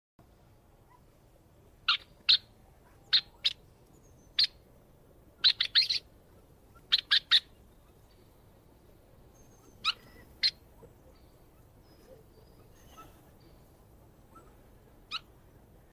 Frostfeather Bird Activity & sound effects free download
About Frostfeather Bird - Activity & Mp3 Sound Effect